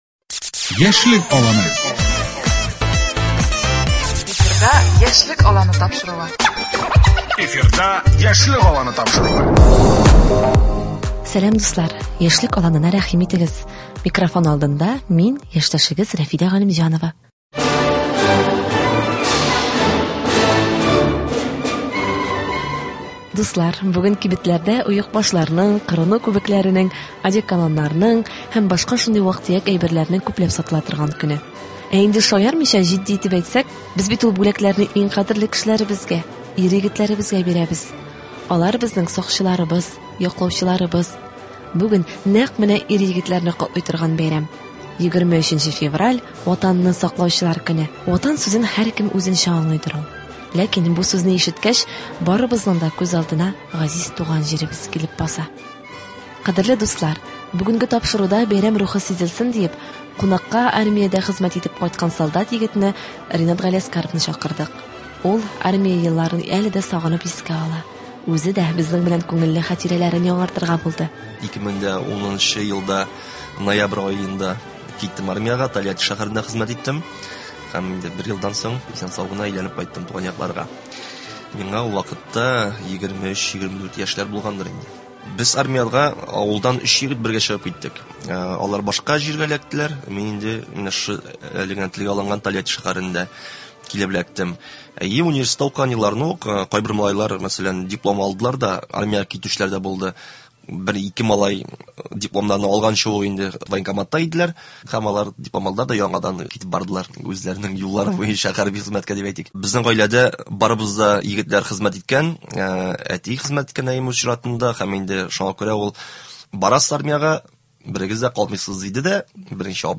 күңелле әңгәмә